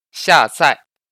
ネイティブの発音付きなので会話にも役立ちます。
Xià zài
シァザイ